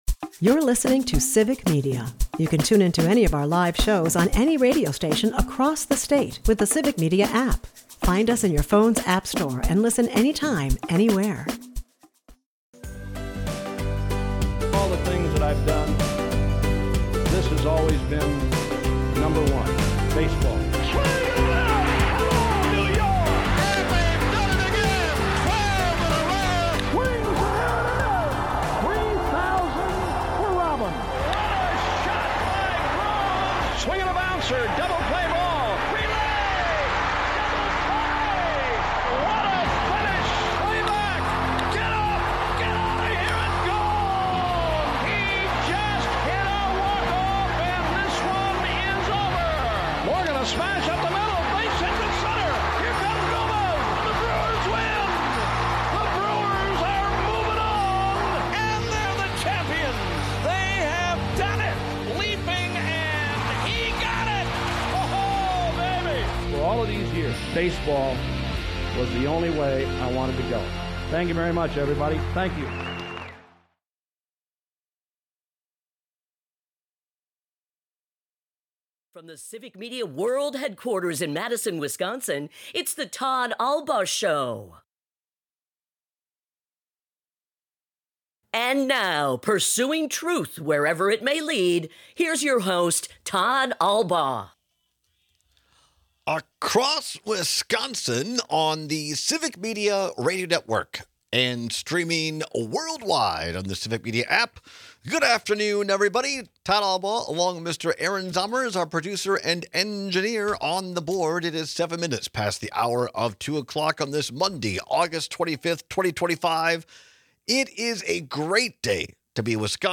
We take some calls and texts with your favorite memories of Ueck.